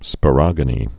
(spə-rŏgə-nē, spô-, spō-)